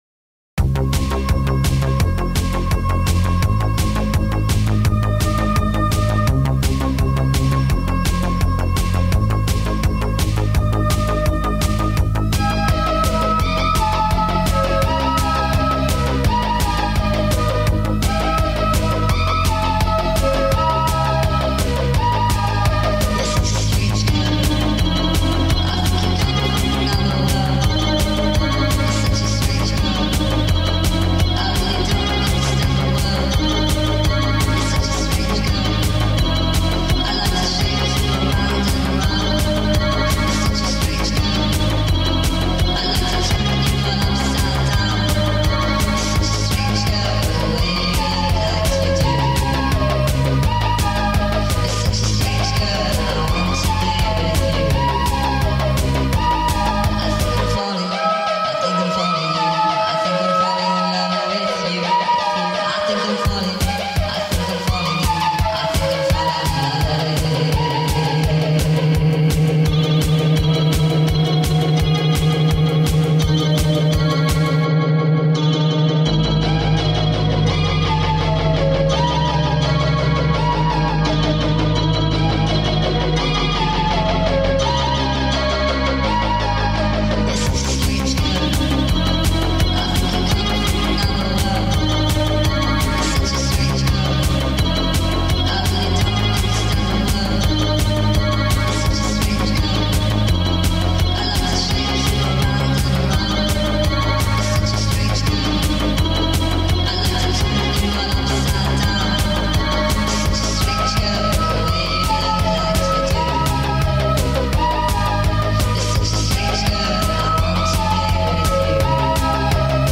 با ریتمی سریع شده
فانک